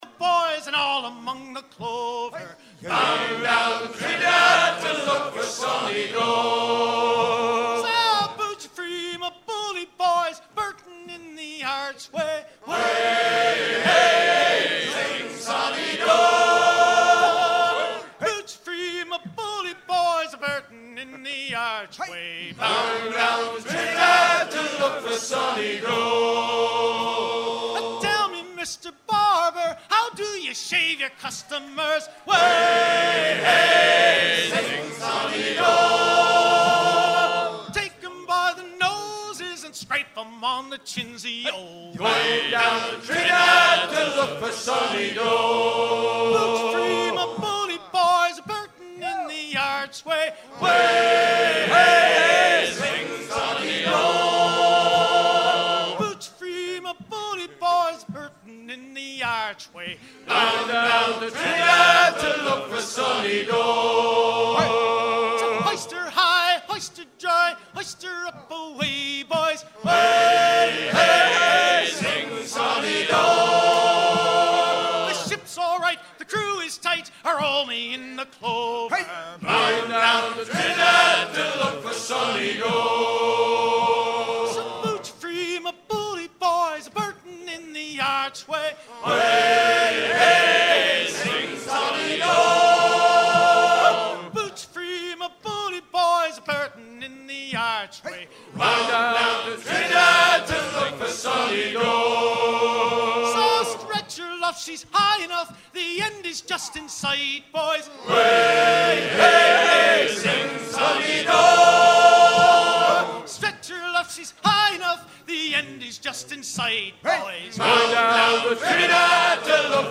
à virer au cabestan
maritimes
Pièce musicale éditée